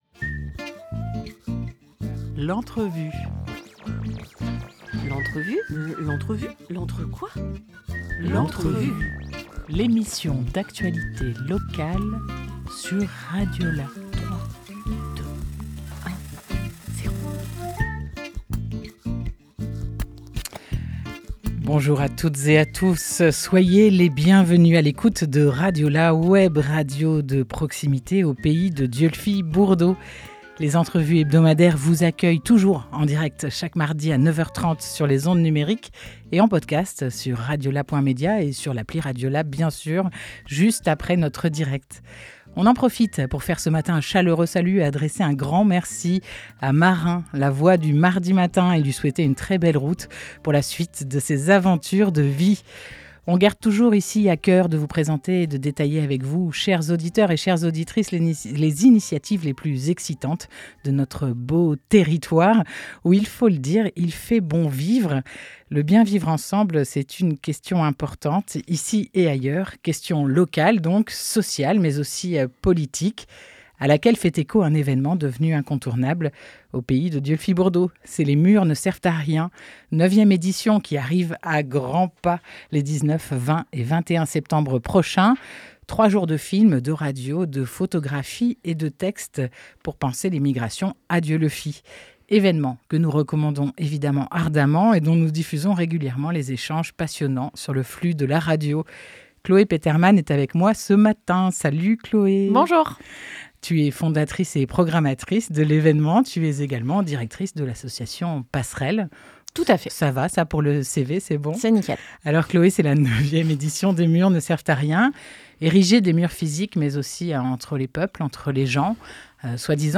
9 septembre 2025 11:24 | Interview